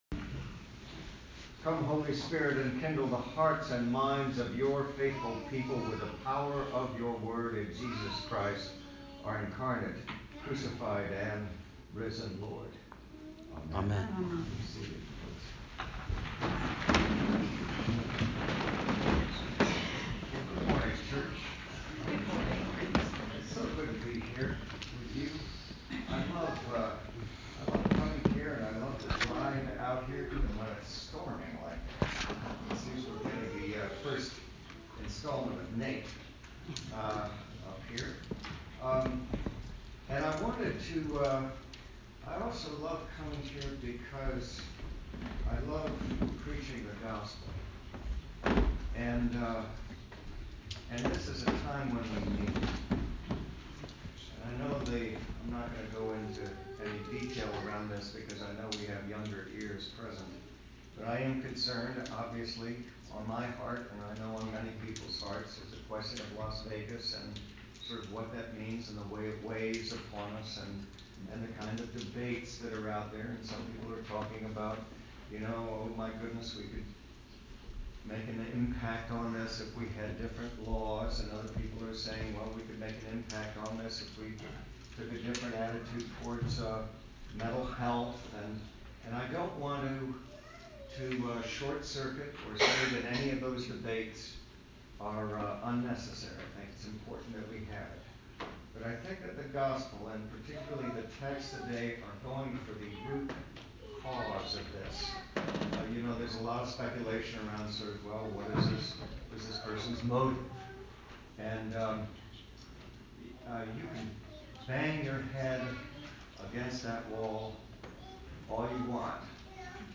Bp. Dorsey’s Sermon – Annual Visit 2017
Rt. Rev. Dorsey McConnell came for his yearly visit this past weekend. Hear his sermon based on the lessons for Proper 22.